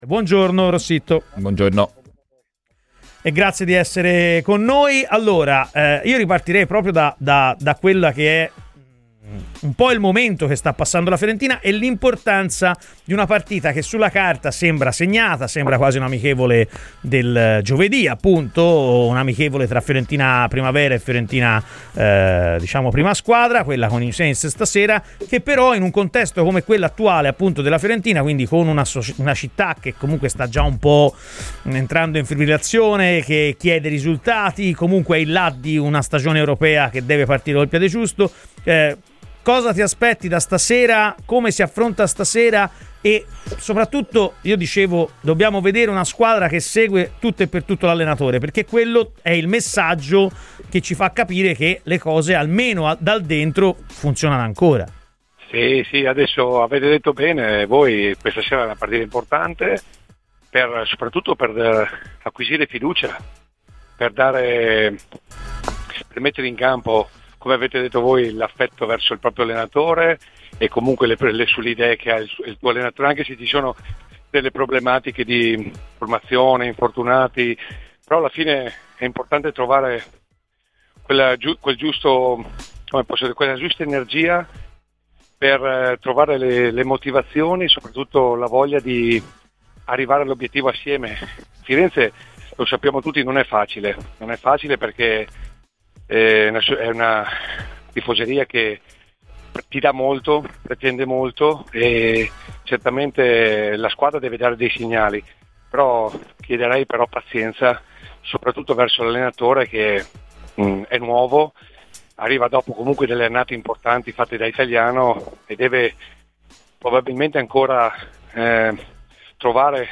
A poche ore dal debutto nel nuovo girone di Conference League, Fabio Rossitto, ex viola che di notti europee se ne intende, ha parlato a Radio FirenzeViola dicendo cosa si aspetta dalla partita di...